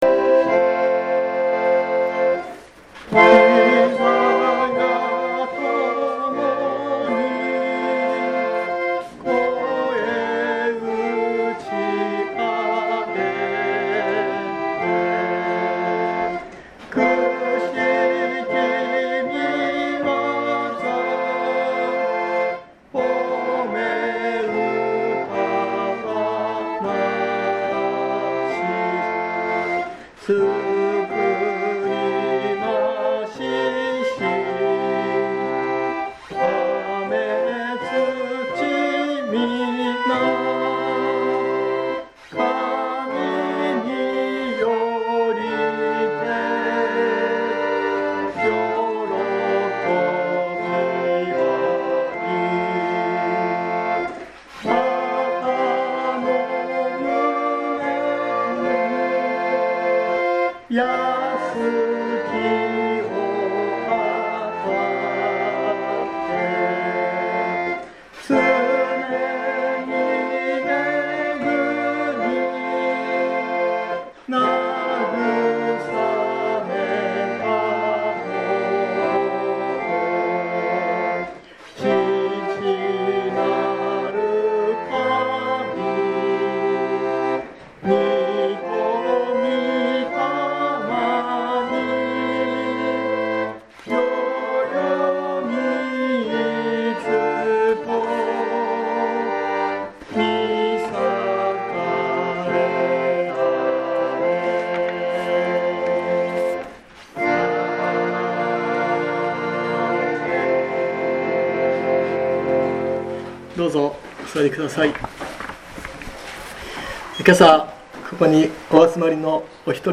日曜朝の礼拝